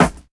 88 SNARE 2.wav